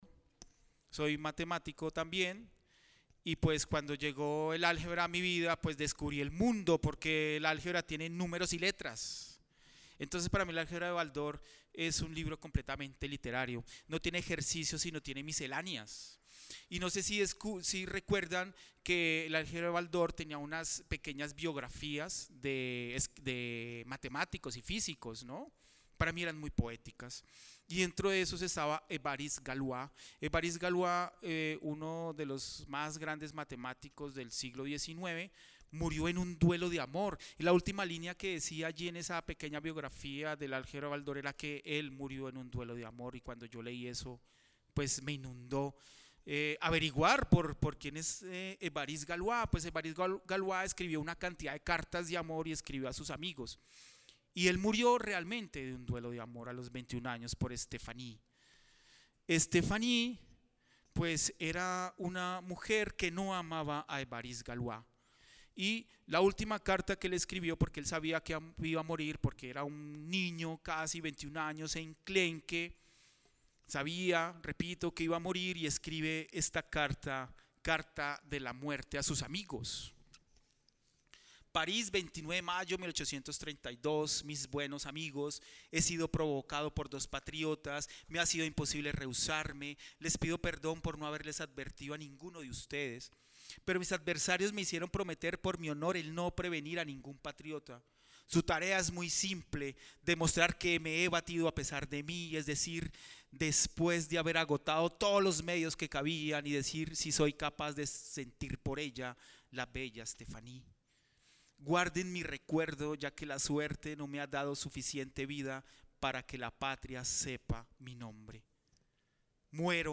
conversaron acerca de la relación entre poesía y ciencia en la conferencia Estado Sólido: Poesía y Ciencia, en el segundo día de actividades de la Feria Internacional del libro de Guadalajara 2022.